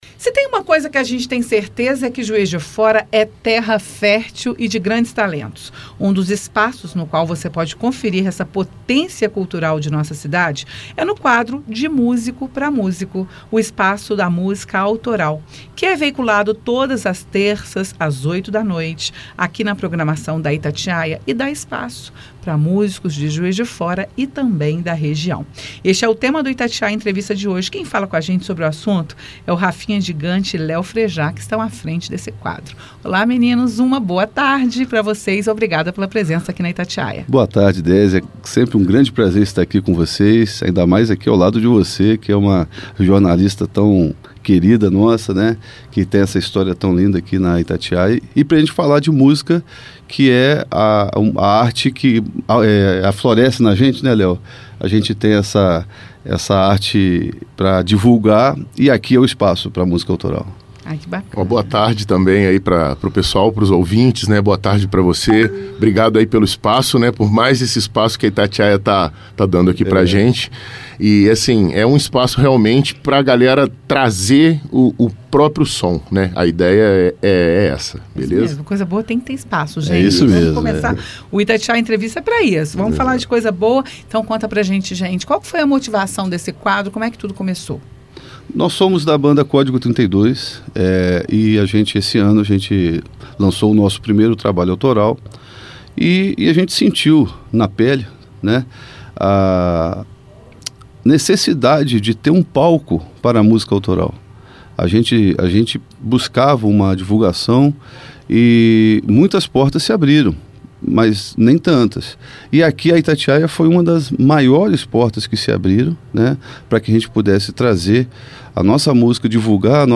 Itatiaia-Entrevista-De-Musico-Para-Musico.mp3